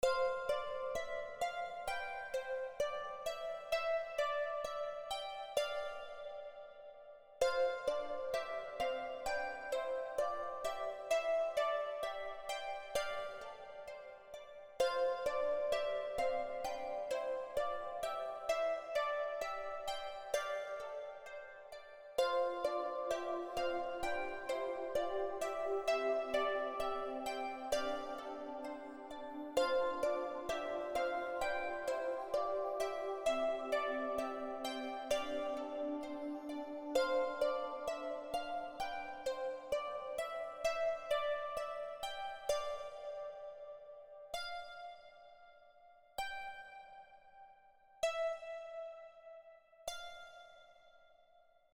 I made this one many years ago, and still use it to this day. it’s using the harp from U-he Zebra.